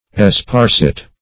Esparcet \Es*par"cet\, n. [F. esparcet, esparcette, ['e]parcet,